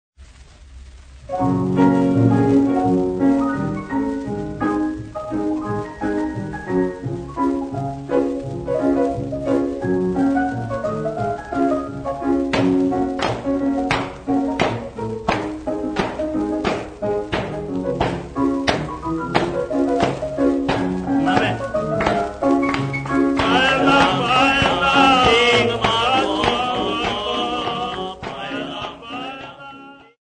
Popular music--Africa
Dance music
Dance music--Caribbean Area
Wedding song accompanied by a piano and clapping